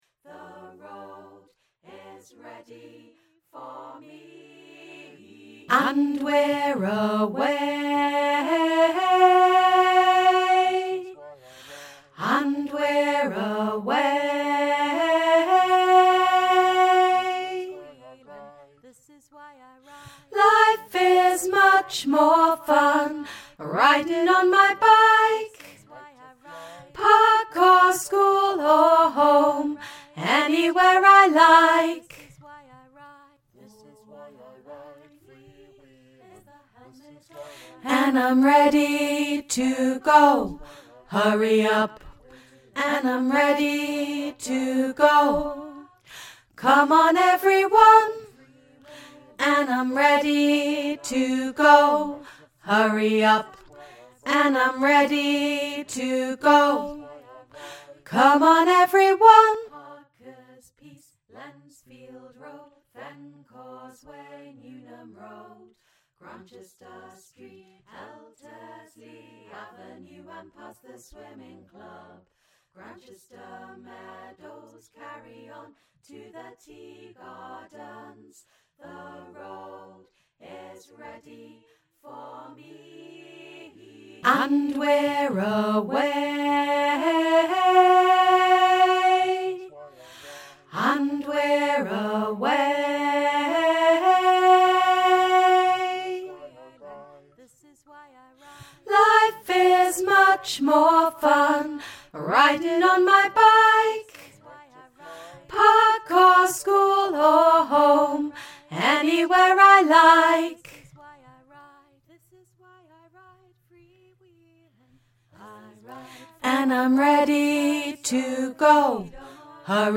NOTE: This audio file is a demo version and is for the purpose of helping the choir leader teach their choir the parts of the song.